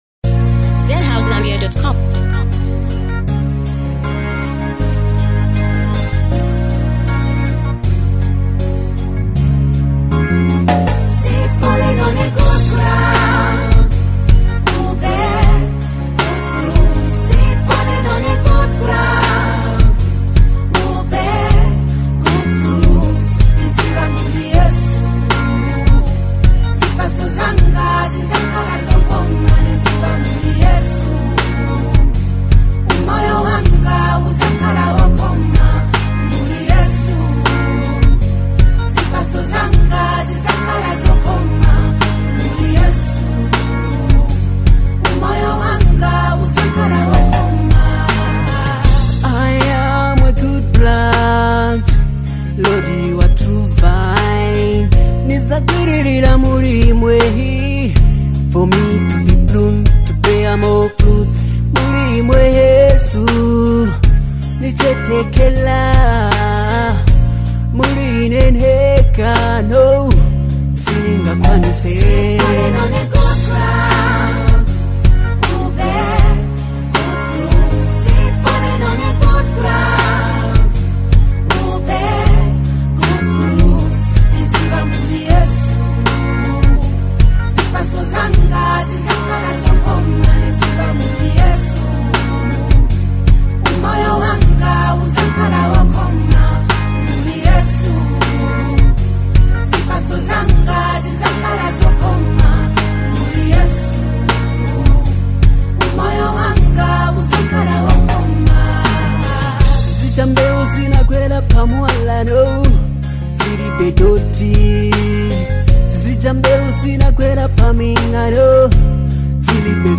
spirit-filled gospel song
With heartfelt vocals and a divine melody